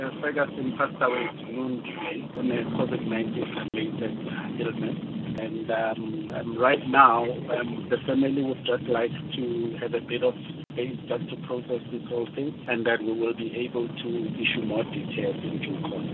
confirmed the news by speaking to Kaya 959 newsdesk on Friday afternoon.